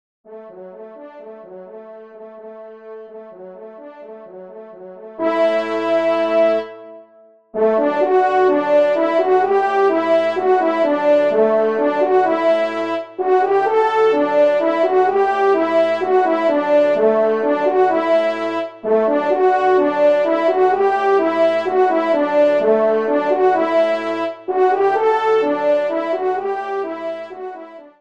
TON SIMPLE :
Pupitre 1° Cor (en exergue)